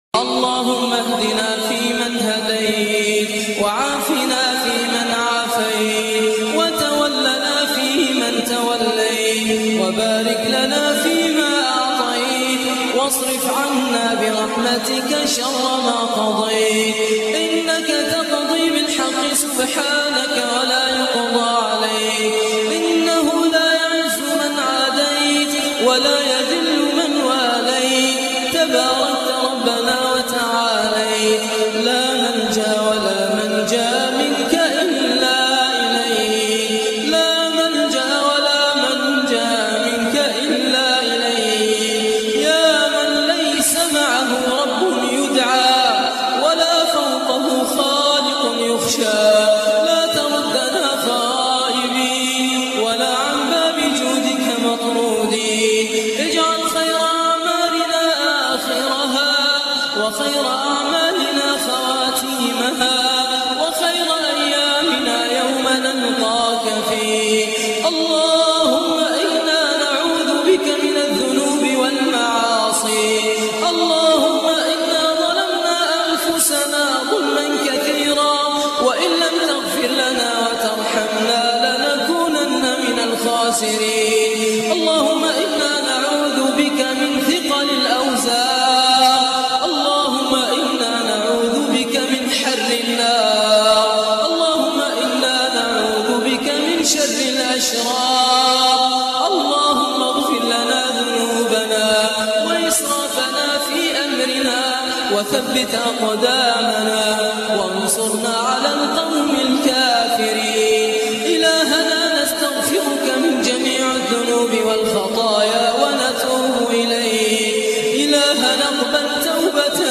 دعاء خاشع ومؤثر
تسجيل لدعاء خاشع ومميز